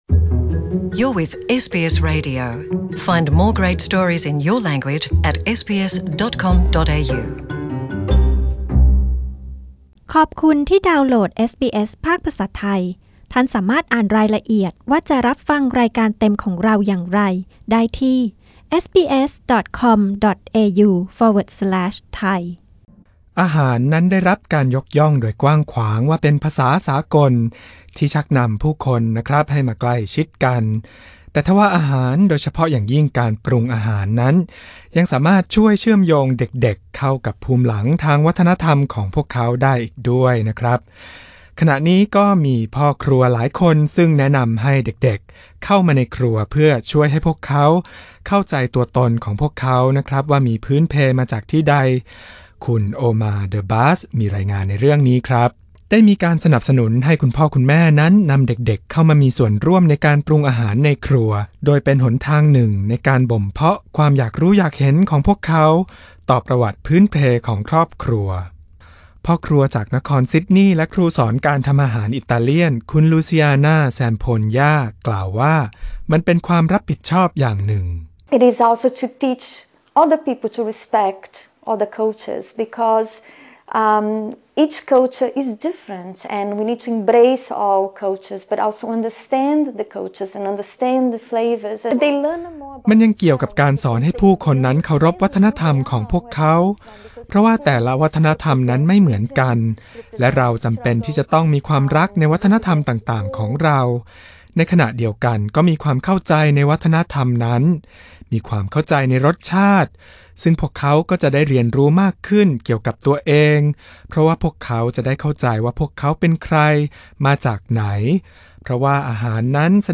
กดด้านบนเพื่อฟังรายงานเรื่องนี้